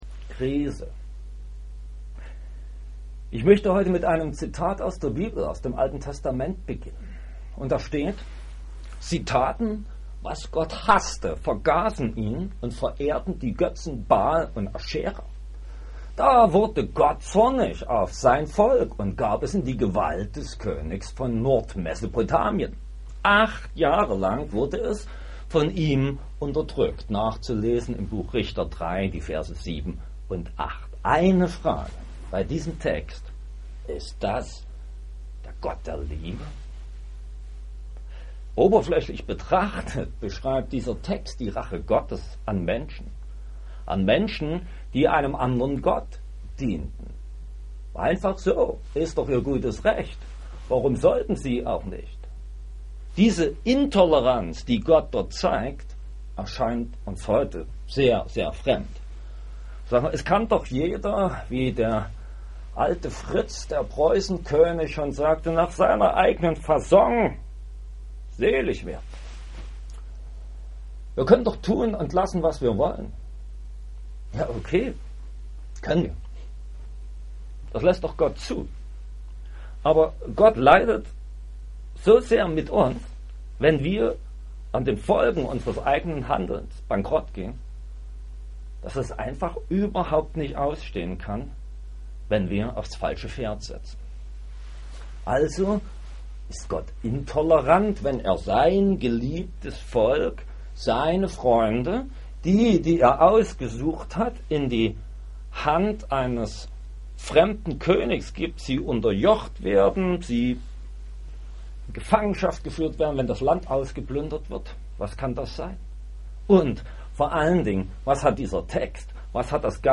Überblick in 4 Minuten Video-Predigt Audio-Predigt Krise.(MP3) Zum Nachlesen » PDF-Download Fragen, Anregungen, Bemerkungen?